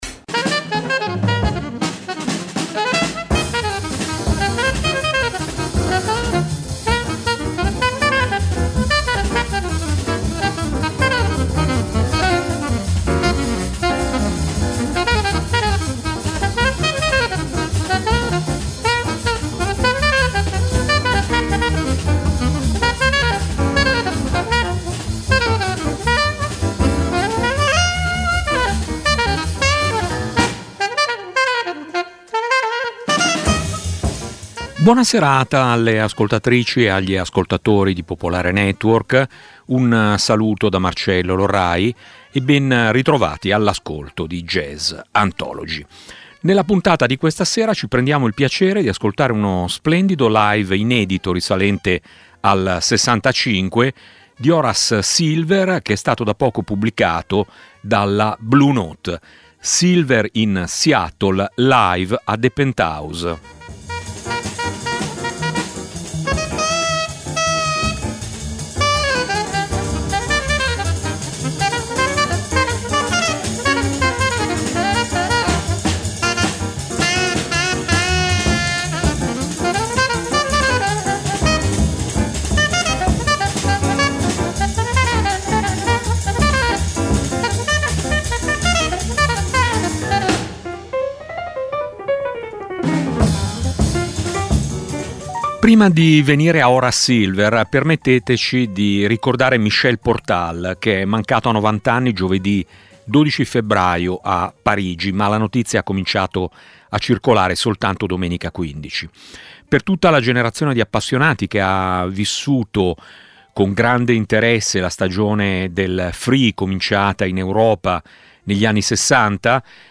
dal vivo nel '65
con un quintetto
al sax tenore
alla tromba
al contrabbasso
alla batteria
hard bop